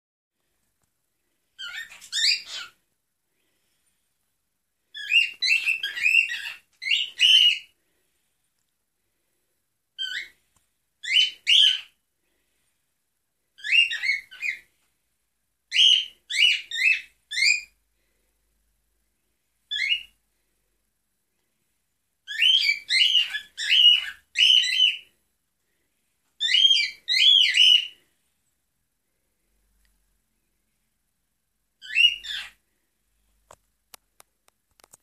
Unsere Nymphensittiche
Zu unserer Überraschung kann Fritzi schön singen (Fritzi1.mp3,